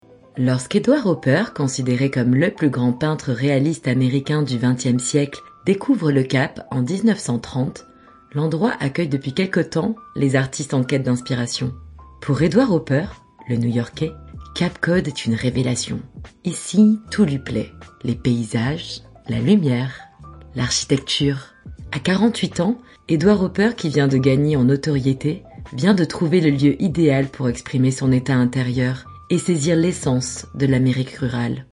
Voix-off- Maquette Documentaire
- Basse